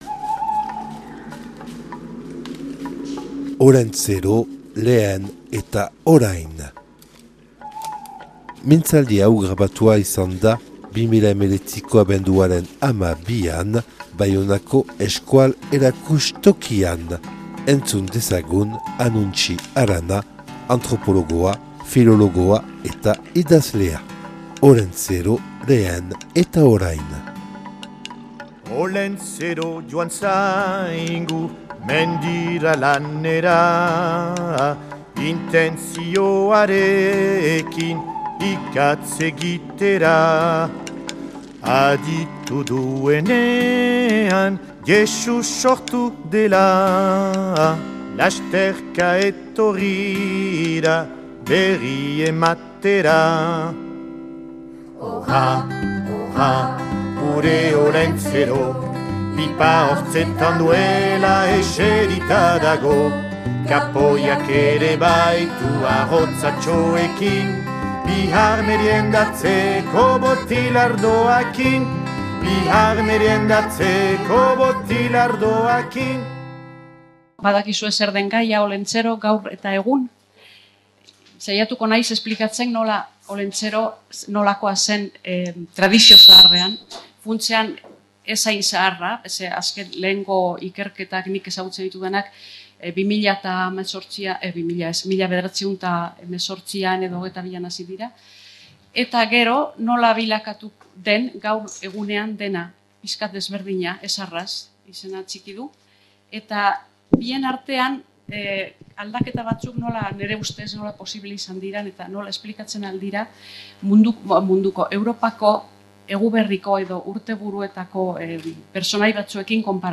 (Euskal Museoan grabatua 2019 abenduaren 12an)